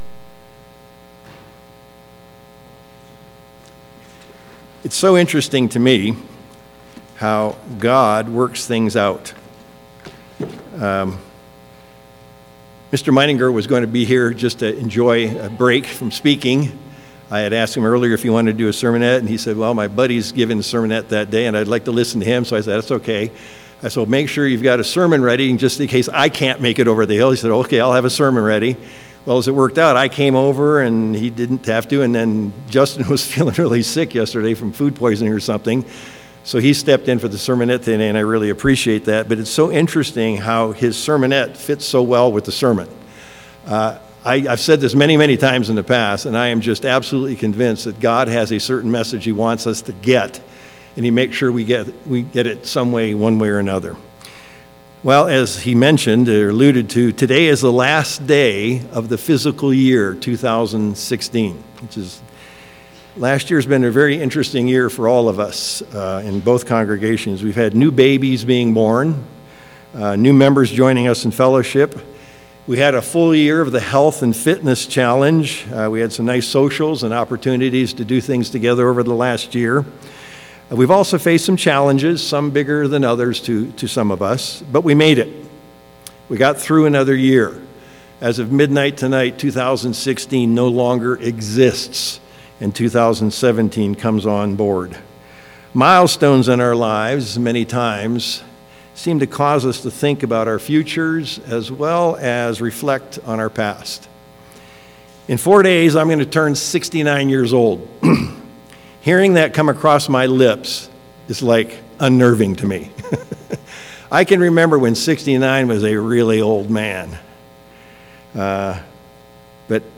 Sermons
Given in Reno, NV